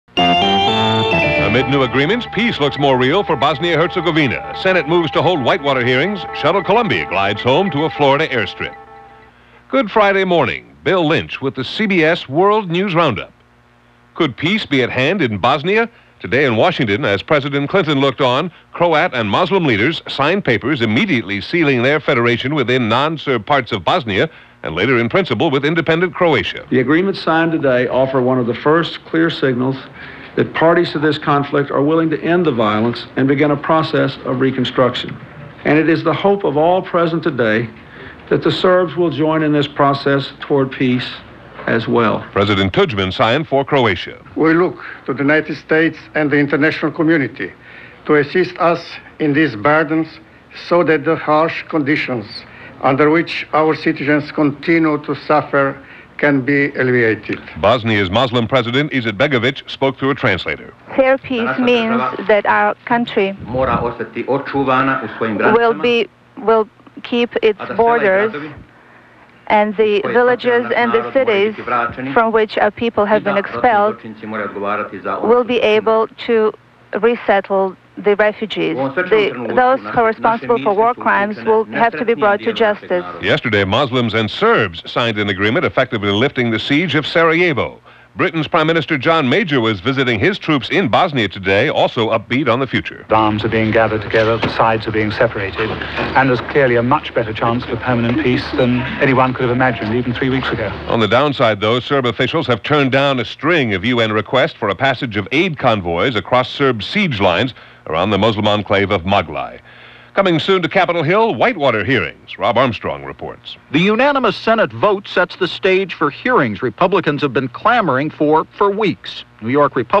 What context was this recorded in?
And that’s just a small sample of what went on this March 18th 1994 as reported by The CBS World News Roundup.